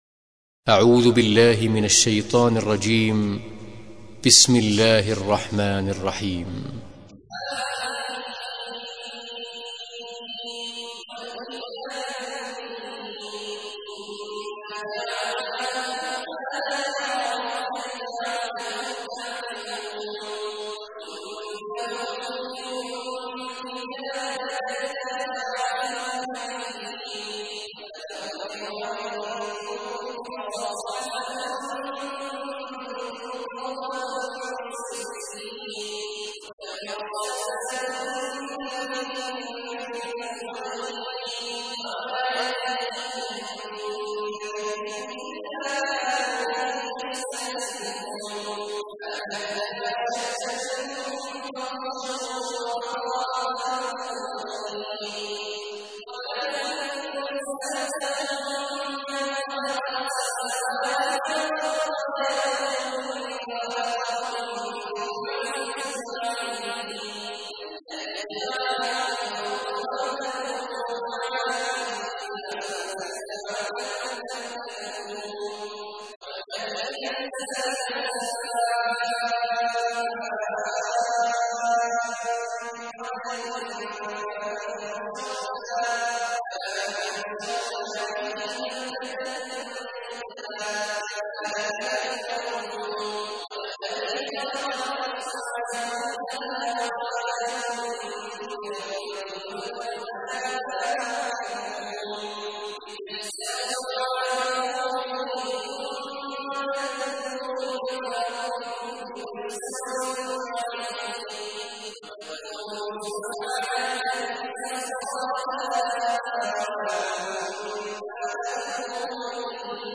تحميل : 43. سورة الزخرف / القارئ عبد الله عواد الجهني / القرآن الكريم / موقع يا حسين